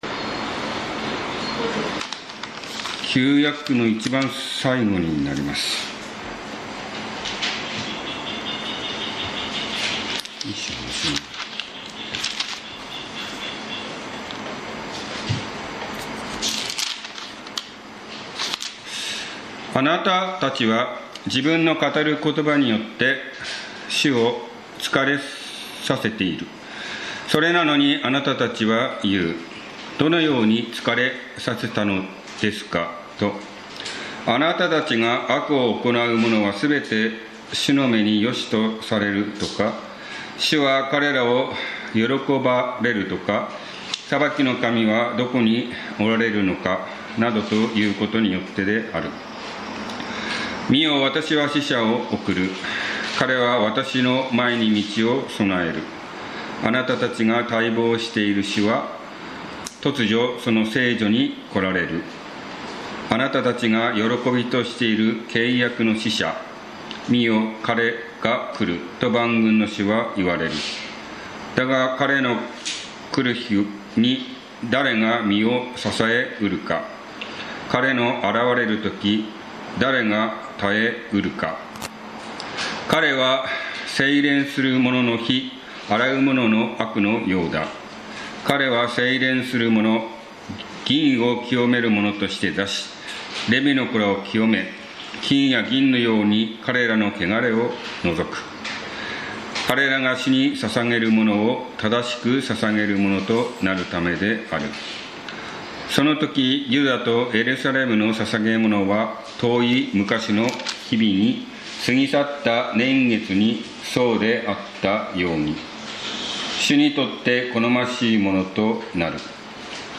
見よ、契約の使者が来る 宇都宮教会 礼拝説教